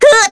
Scarlet-Vox_Down_kr.wav